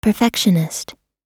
Perfectionist [pəˈfɛkʃ(ə)nɪst]
perfectionist__us_1.mp3